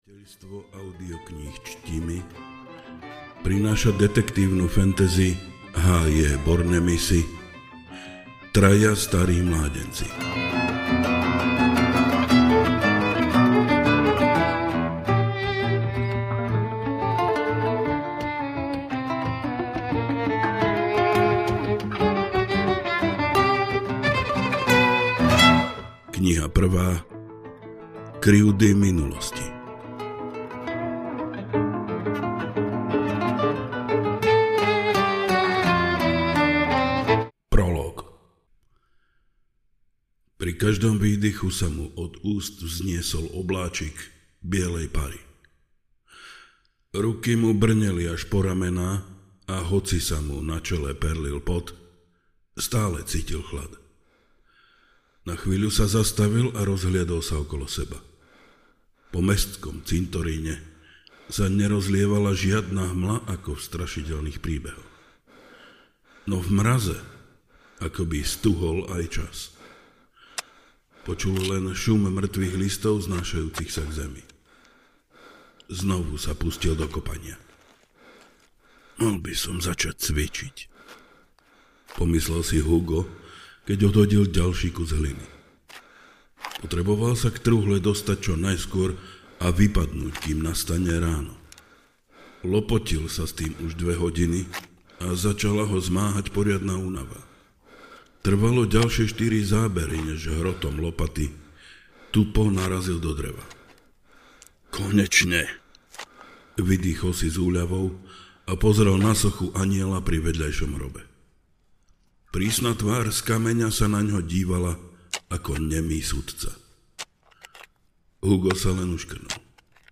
Traja starí mládenci audiokniha
Ukázka z knihy